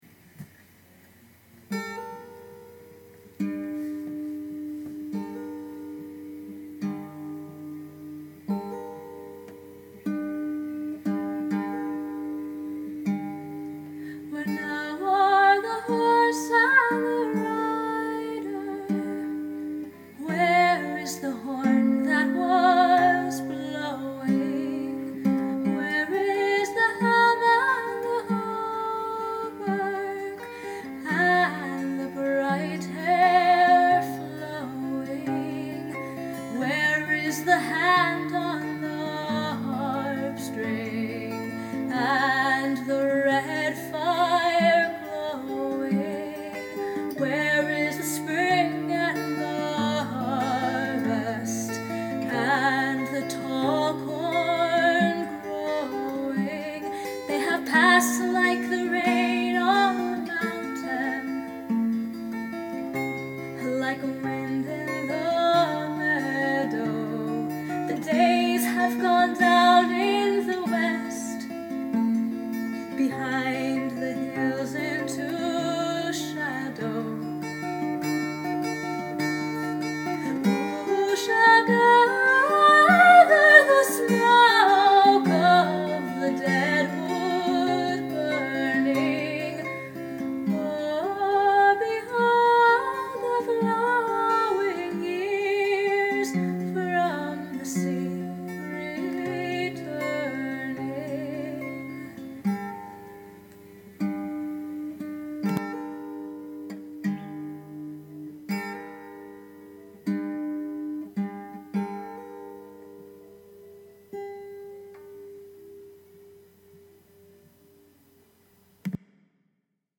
The guitar part is also more defined here!